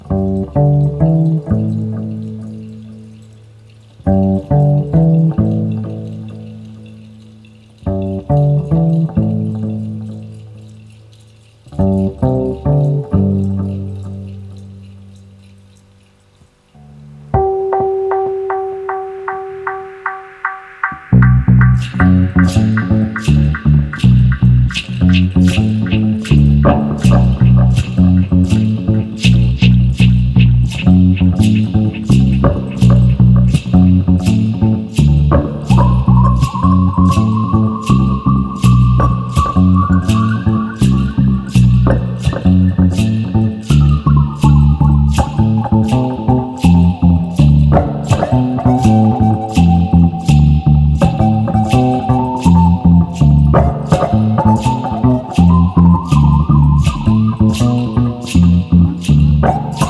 オールアナログにこだわり、すべての楽器を自分で演奏し、16 トラックのオープンリール MTR への録音